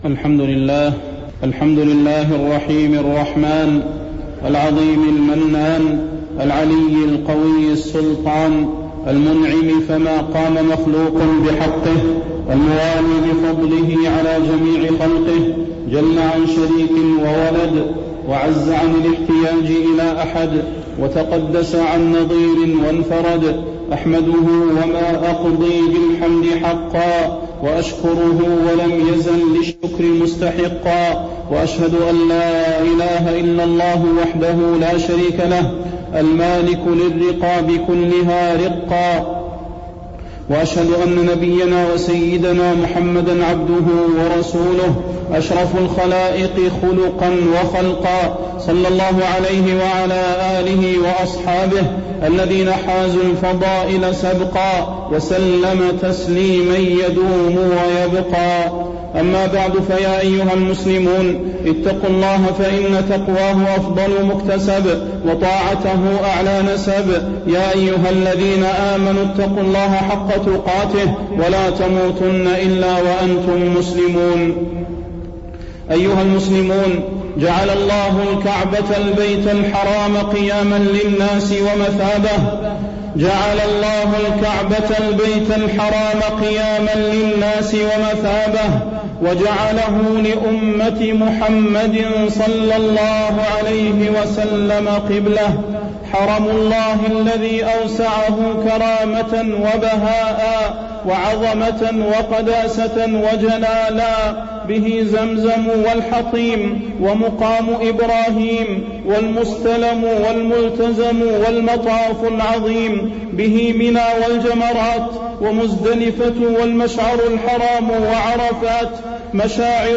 تاريخ النشر ١ ذو الحجة ١٤٢٧ هـ المكان: المسجد النبوي الشيخ: فضيلة الشيخ د. صلاح بن محمد البدير فضيلة الشيخ د. صلاح بن محمد البدير فريضة الحج